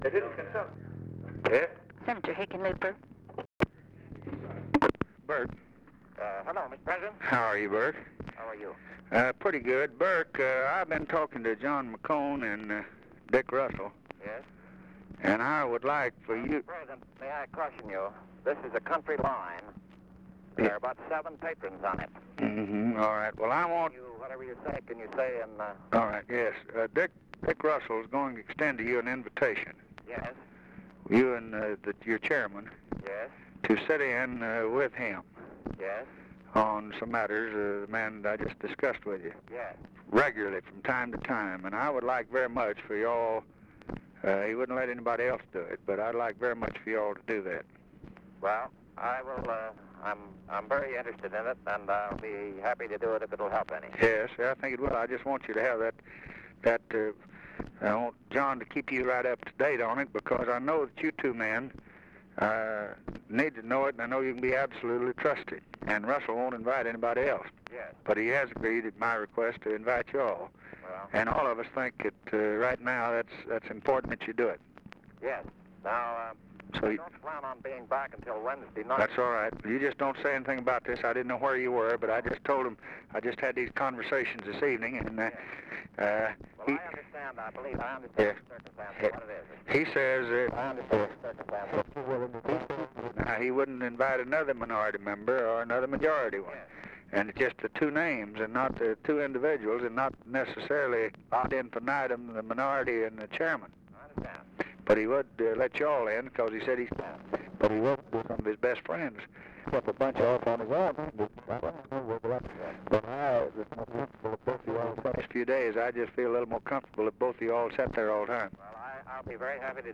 Conversation with BOURKE HICKENLOOPER, November 29, 1963
Secret White House Tapes